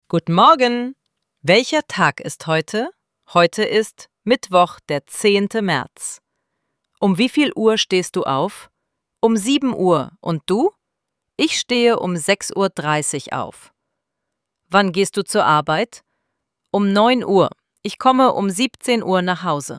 IZGOVOR – MINI DIJALOG:
ElevenLabs_Text_to_Speech_audio-53.mp3